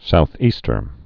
(south-ēstər, sou-ē-)